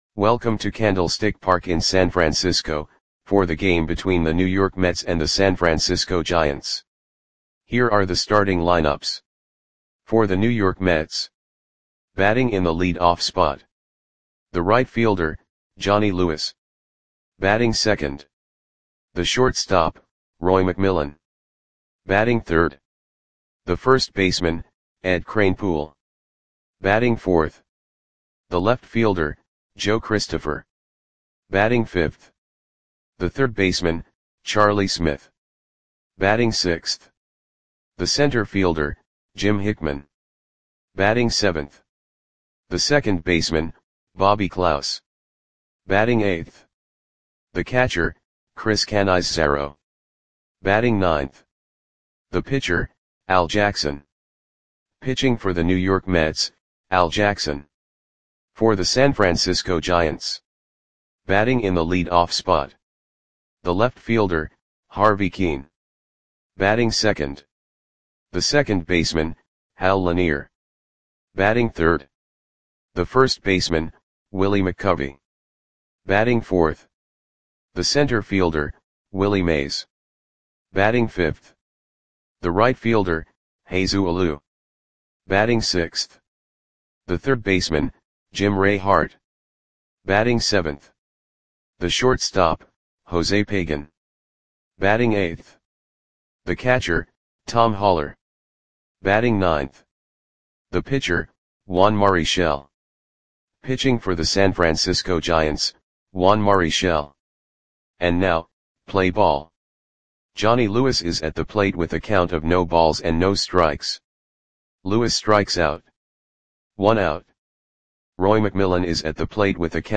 Audio Play-by-Play for San Francisco Giants on April 25, 1965
Click the button below to listen to the audio play-by-play.